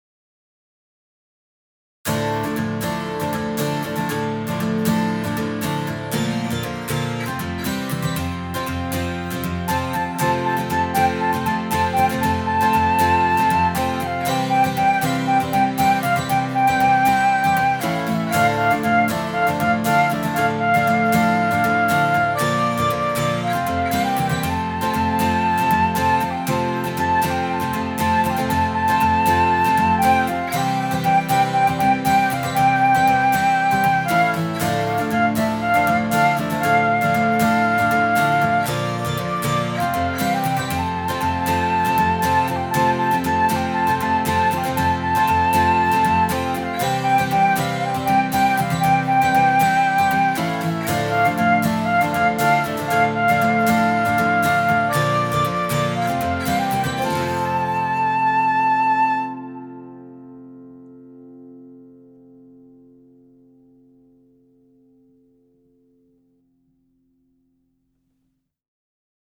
Tradition polonaise
InstrumentalCOUPLET/REFRAIN